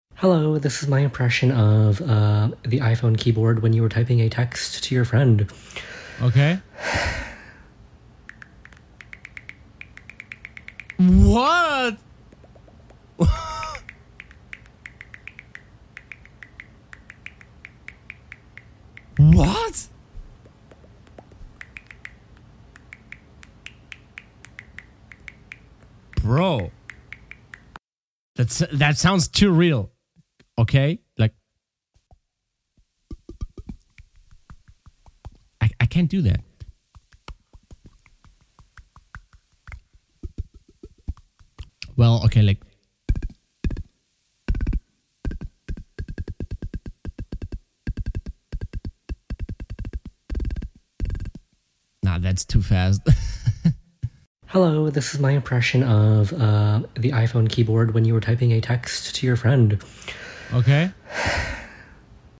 Reallife Keyboard tiping